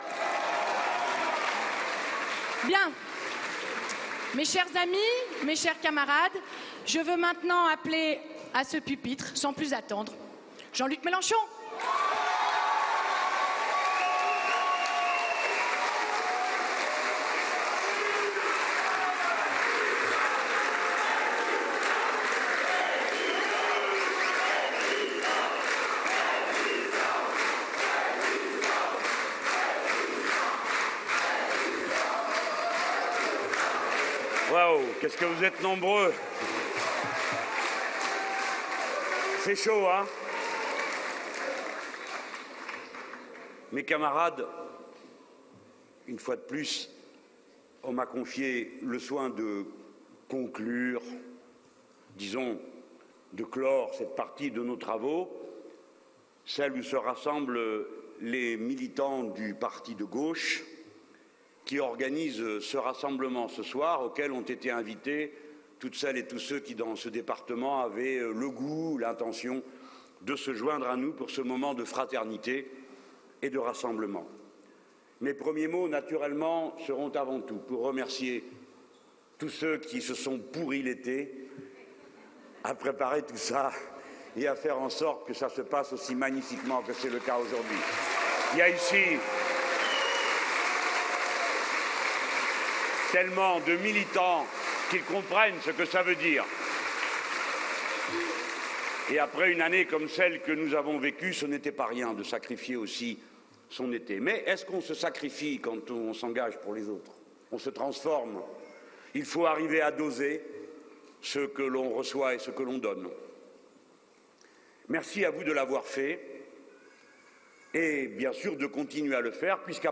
Meeting avec Jean-Luc Mélenchon le 23 août 2013
Intervention de Jean-Luc Mélenchon
Meeting avec Jean-Luc Mélenchon le 23 août 2013 Intervention de Basma Khalfaoui Intervention de Jean-Luc Mélenchon Meeting à Saint-Martin-d’hères au Palais des sports Pablo Neruda pour la clôture des Remue-Minges du Parti de Gauche ( la retransmission est terminée ).